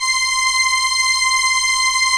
Index of /90_sSampleCDs/Roland LCDP09 Keys of the 60s and 70s 1/STR_ARP Strings/STR_ARP Solina